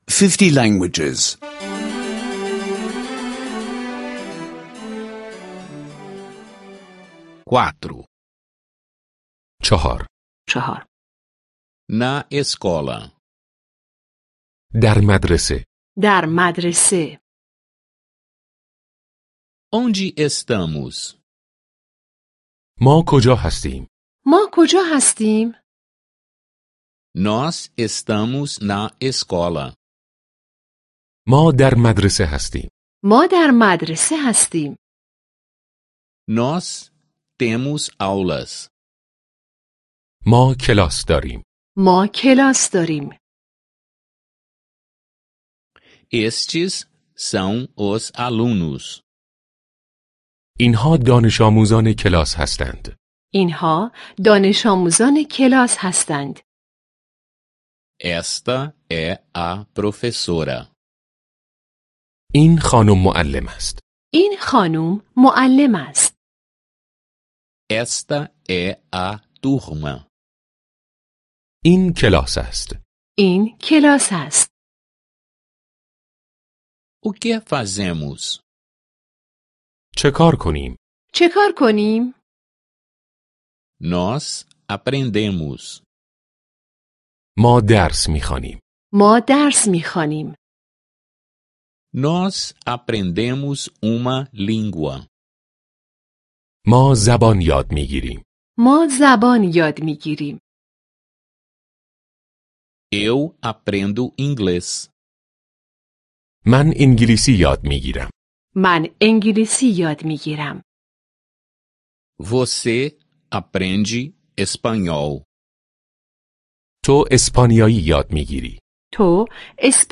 Aulas de persa em áudio — download grátis